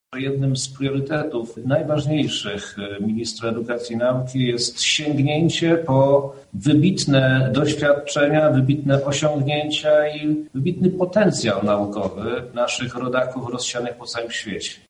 P. Czarnek – mówi minister edukacji i nauki Przemysław Czarnek.